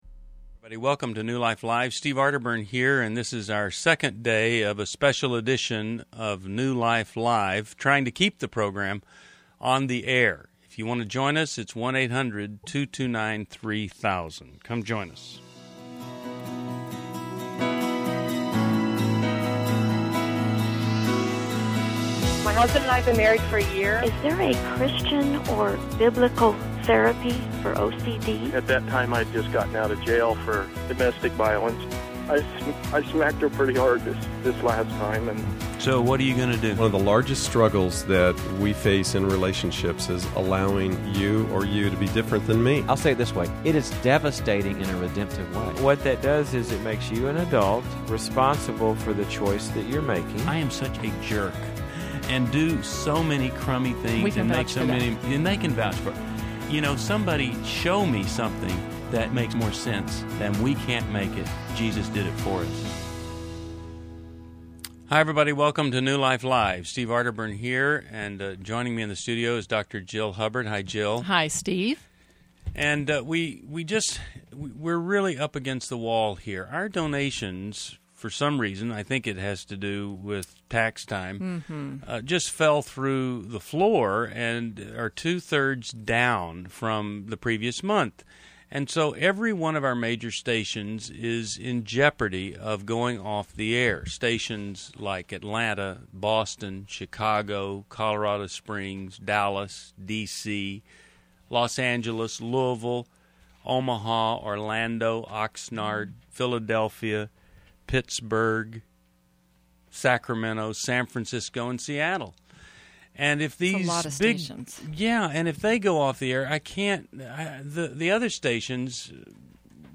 Caller Questions: Why I want to keep New Life Live on the air!